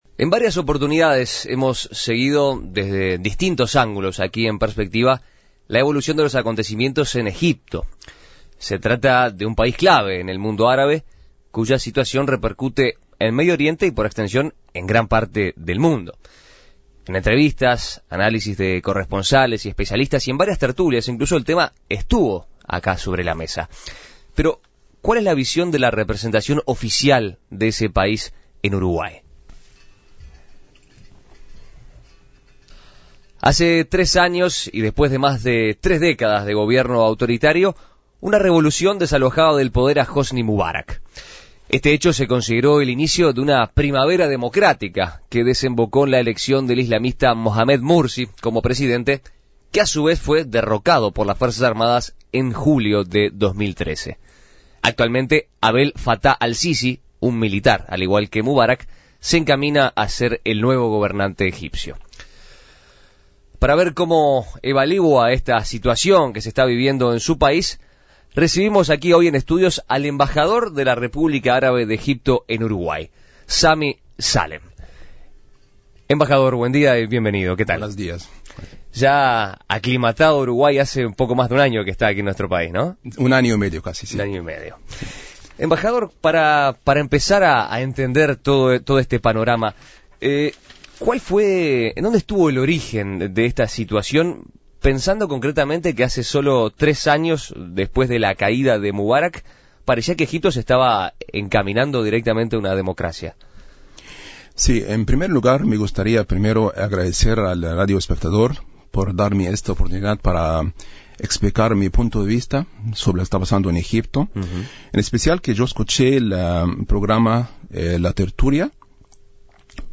Para ahondar en este panorama, En Perspectiva dialogó con el embajador de Egipto en Uruguay, Sami Salem.